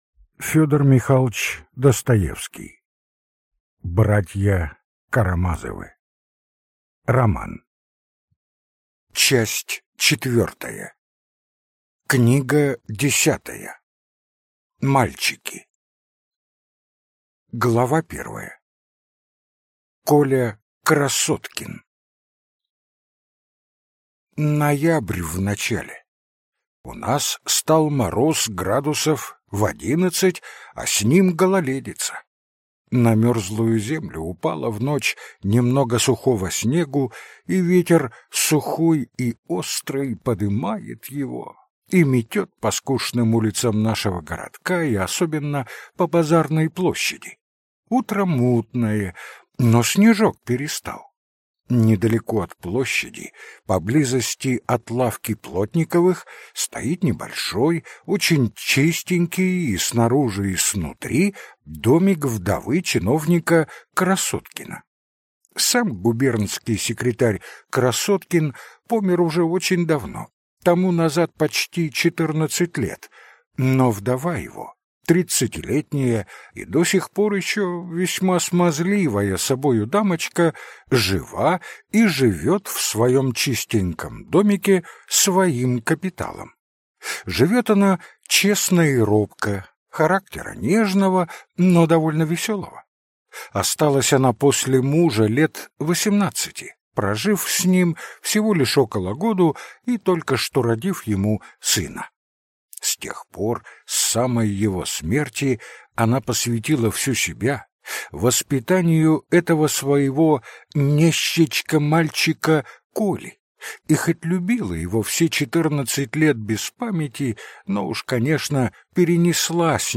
Аудиокнига Братья Карамазовы (Часть 4) | Библиотека аудиокниг